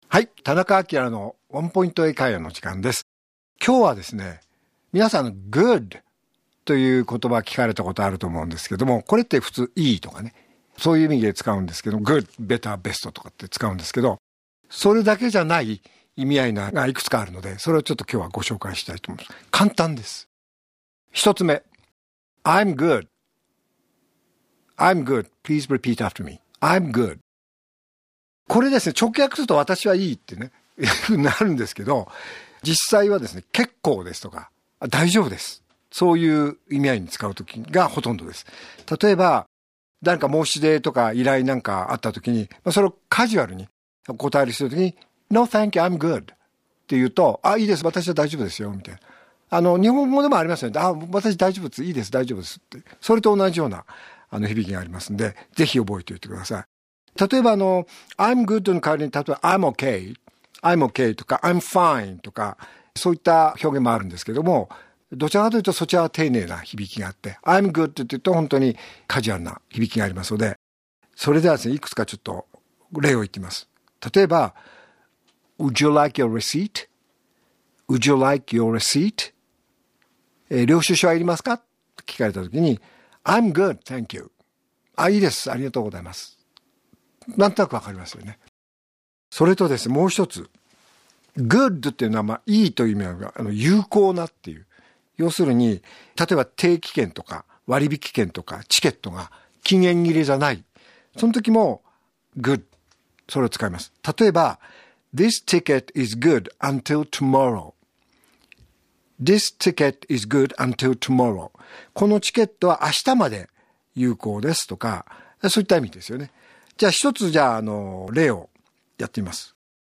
R5.12 AKILA市長のワンポイント英会話